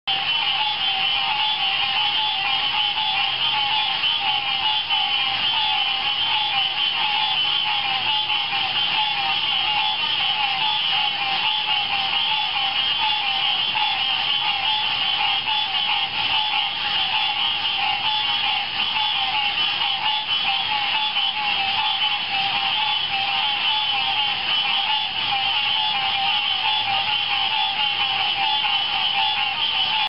These are gathered from choruses of frogs from around the country, and the mp3 of each has been included as well, so you can preview your ringtone first.
This is a large and noisy chorus of Hyla cinerea mostly, and these will make a great attention-getting ringtone!